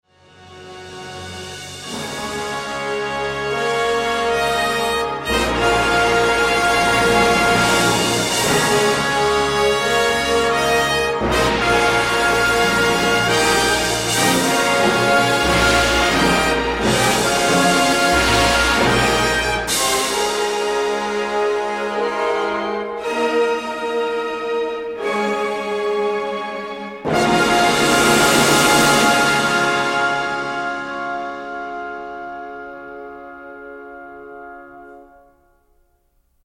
Zaskakująco dobra i ognista jest kulminacja tego ogniwa:
Fritz Reiner, Chicago Symphony Orchestra, 1960, 25:00, RCA Victor